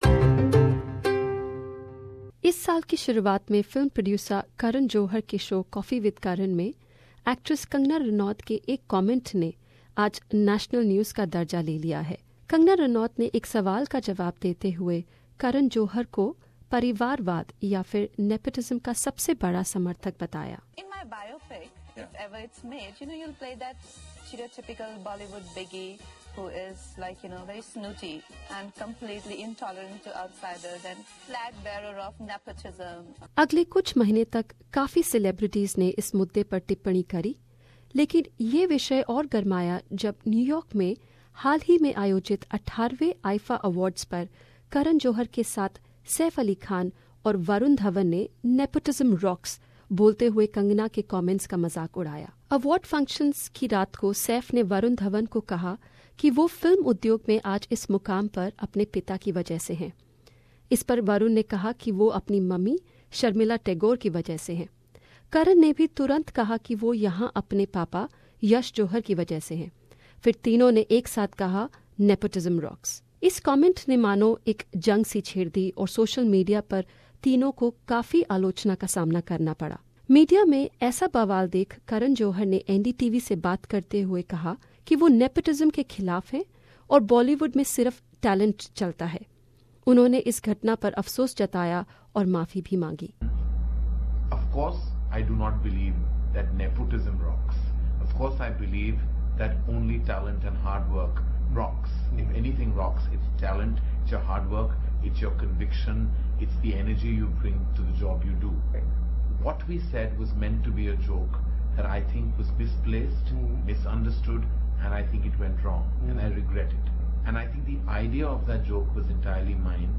A report.